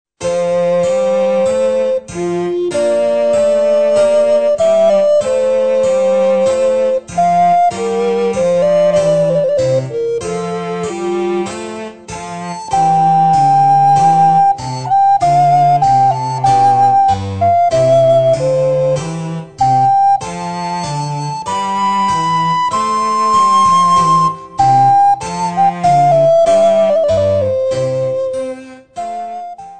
Besetzung: Altblockflöte und Basso continuo